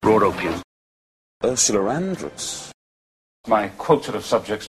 Dalton’s “intrusive” r tends to be the tap. Here he is saying raw opium, then Ursula Andress (from an interview), then (from a non-Bond film) my quota of subjects:
dalton_intrusive_tap.mp3